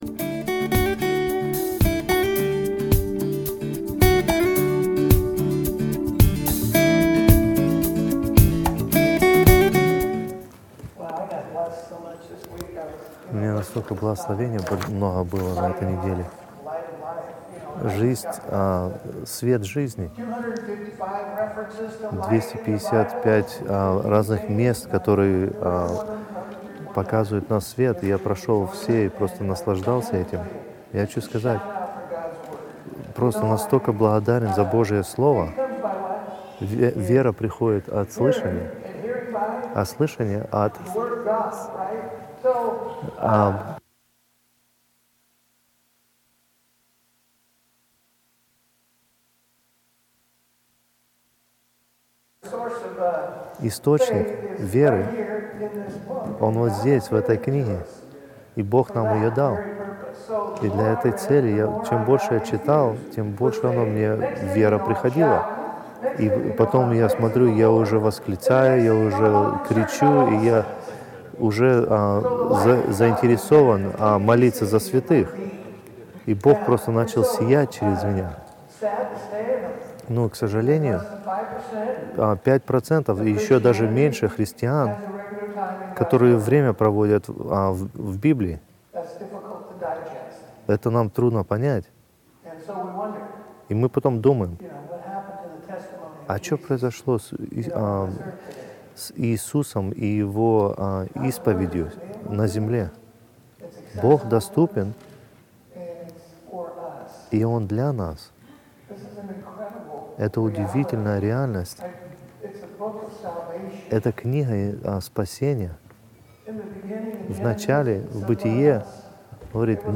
Русские проповеди